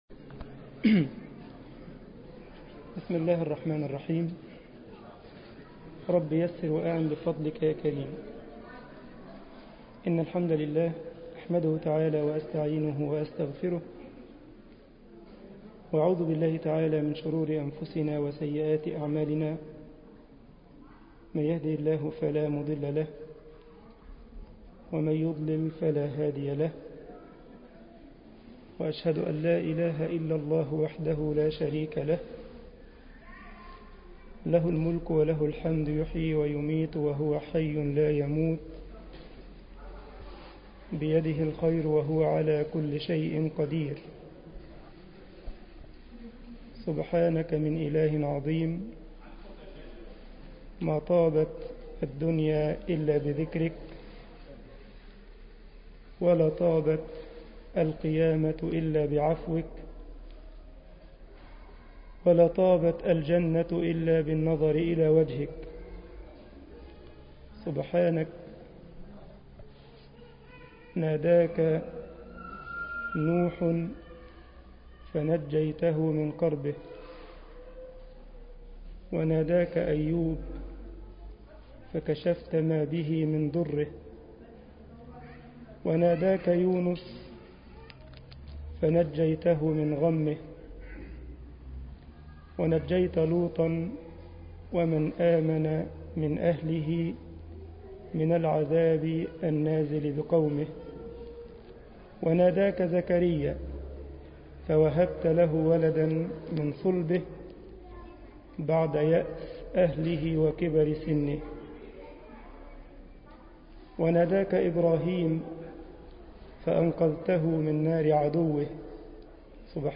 مسجد الجمعية الإسلامية بالسارلند ـ ألمانيا محاضرة